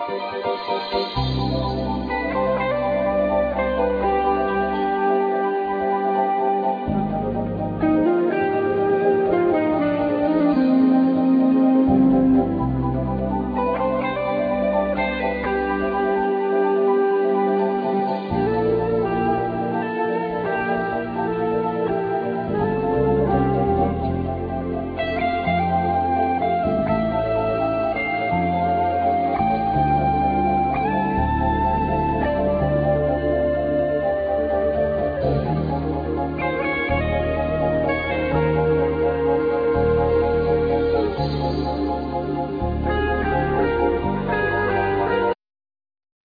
piano, accordion, keyboards
keyboards, piano, programming
tenor and soprano saxophones
acoustic bass
drums
percussion
electric bass
oboe
drums, tabla, percussion
clarinet